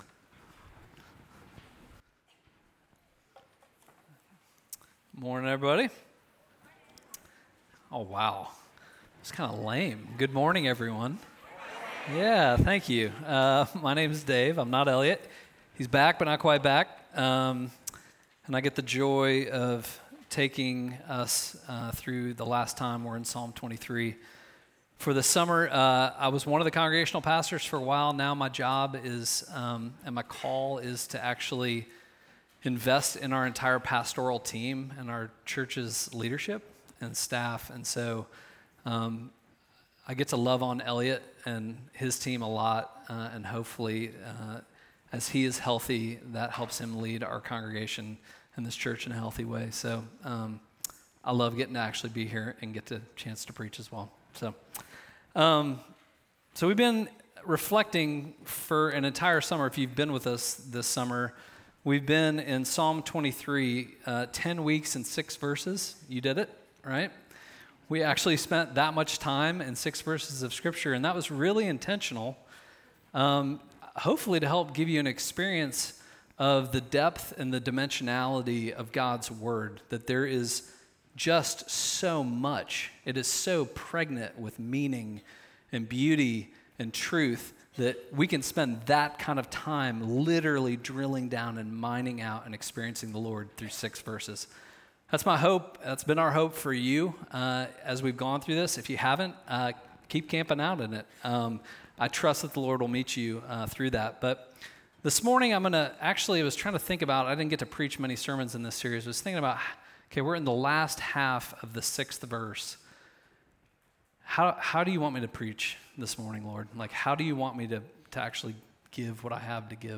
Midtown Fellowship 12 South Sermons